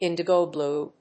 índigo blúe